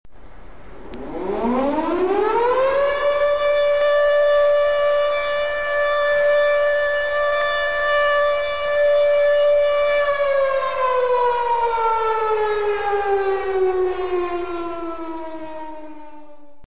Siren_edit.mp3